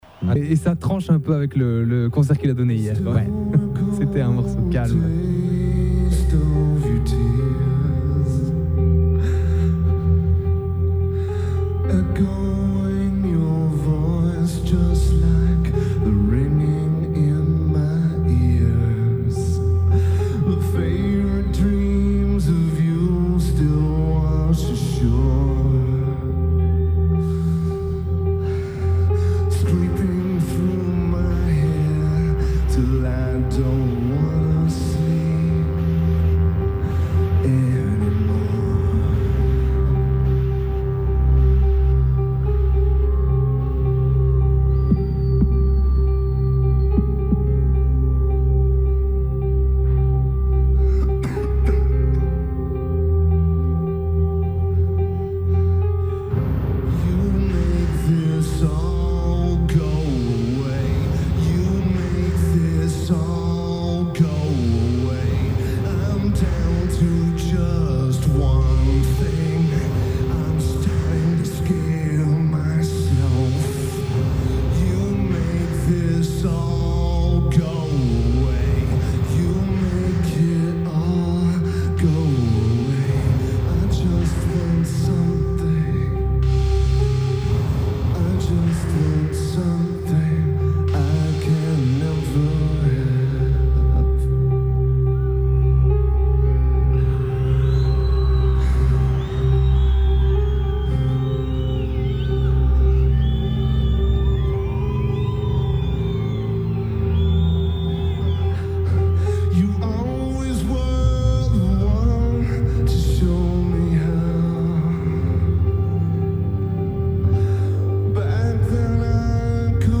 Rock Werchter
Guitar
Keyboards/Bass/Backing Vocals
Drums
Lineage: Audio - PRO (FM Broadcast)